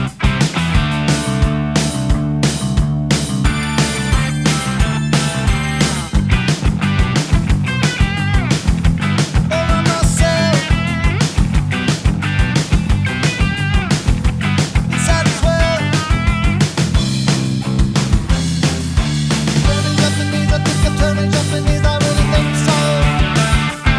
no Backing Vocals Punk 3:43 Buy £1.50